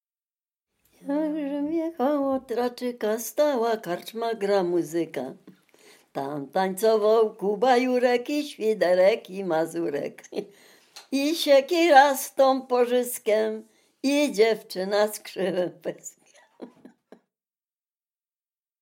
Dolny Śląsk, powiat bolesławiecki, gmina Nowogrodziec, wieś Zebrzydowa
Array żartobliwe przyśpiewki